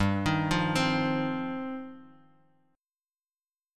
G7sus4#5 chord